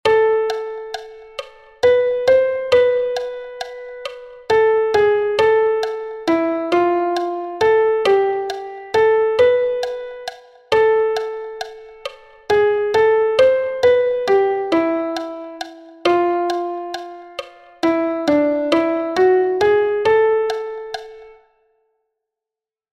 Here there are four six eight time signature exercises.
1_con_metronomo.mp3